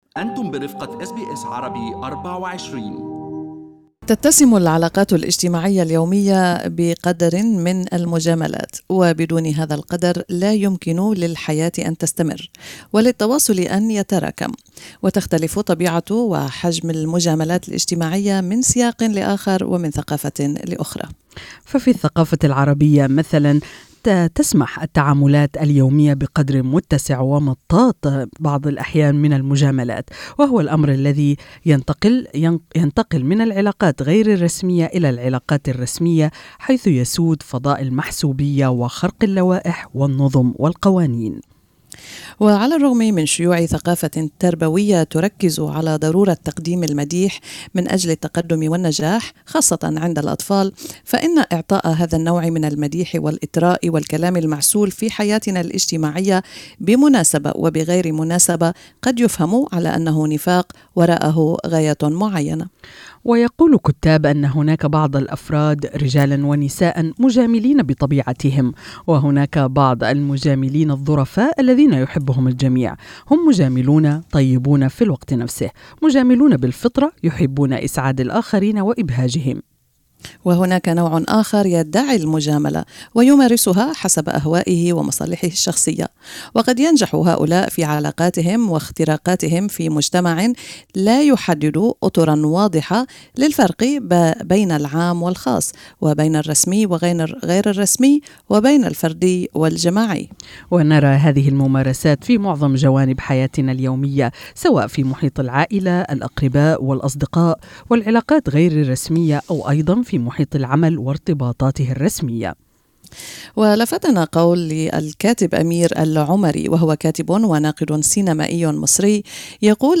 استمعوا إلى اللقاء كاملا تحت المدونة الصوتية في أعلى الصفحة.